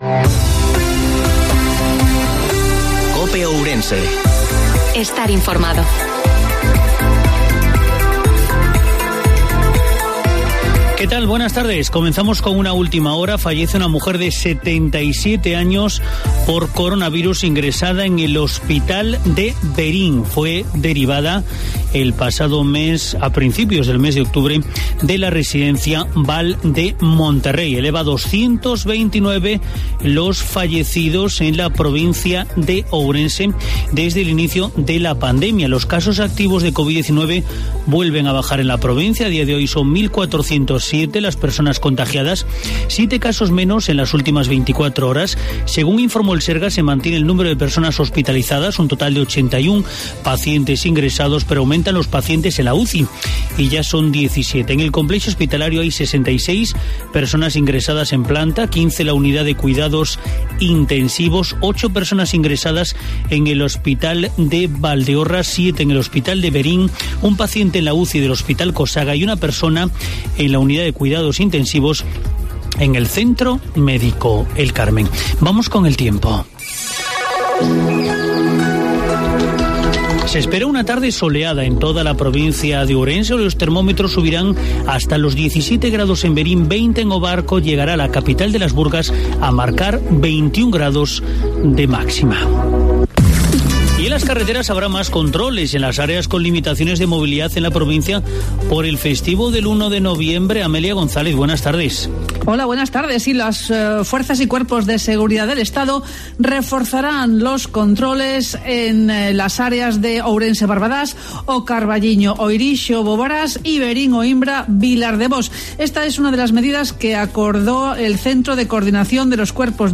INFORMARTIVO MEDIODIA COPE OURENSE